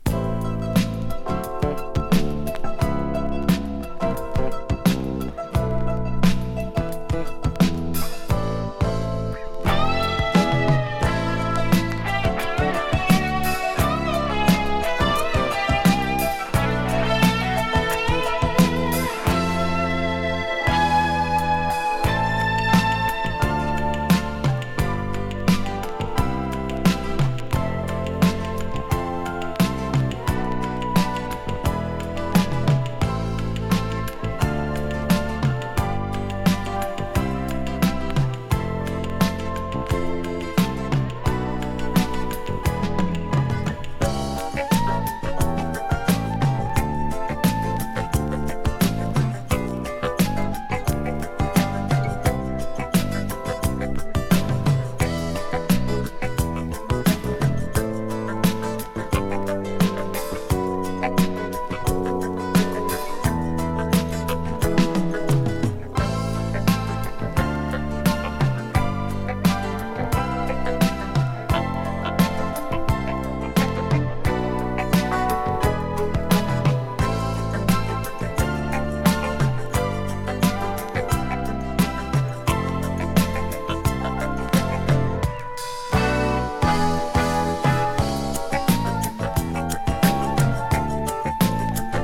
ノイズ出る箇所有。